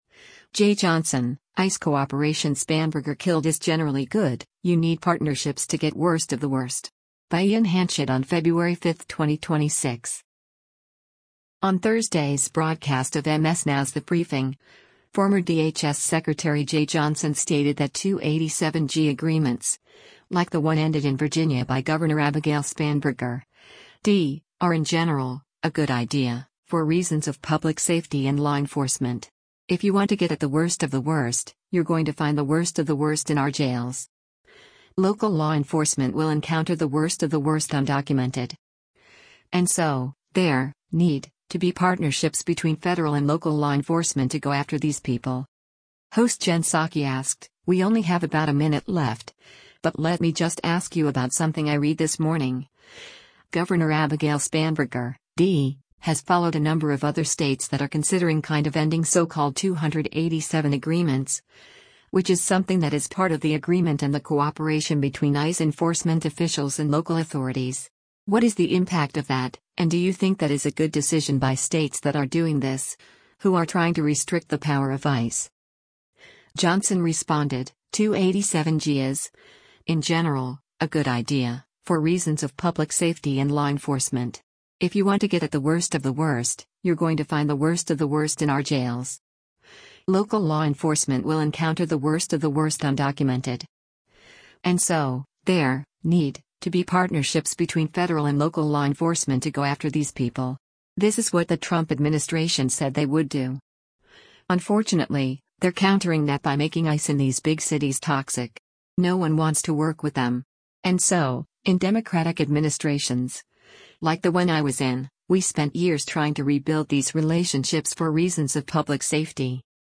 On Thursday’s broadcast of MS NOW’s “The Briefing,” former DHS Secretary Jeh Johnson stated that 287(g) agreements, like the one ended in Virginia by Gov. Abigail Spanberger (D), are “in general, a good idea, for reasons of public safety and law enforcement. If you want to get at the worst of the worst, you’re going to find the worst of the worst in our jails. Local law enforcement will encounter the worst of the worst undocumented. And so, there [need] to be partnerships between federal and local law enforcement to go after these people.”